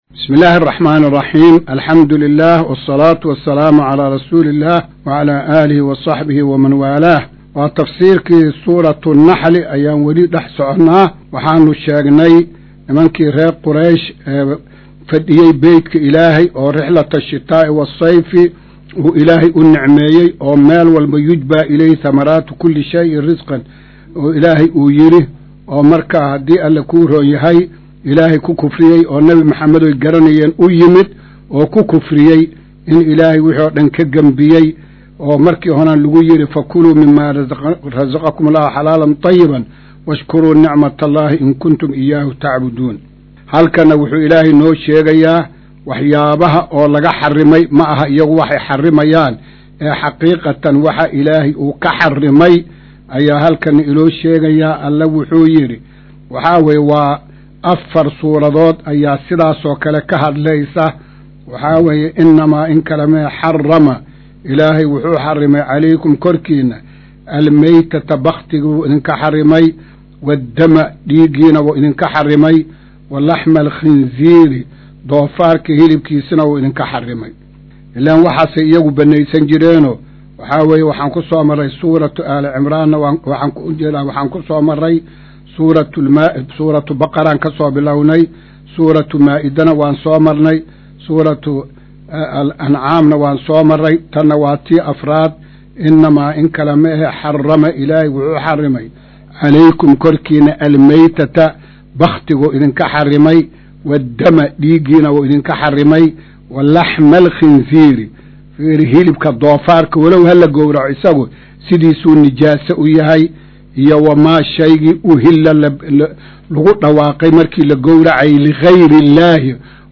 Maqal:- Casharka Tafsiirka Qur’aanka Idaacadda Himilo “Darsiga 136aad”